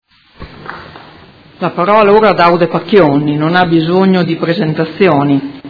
Audio Consiglio Comunale
Seduta del 19 aprile 2018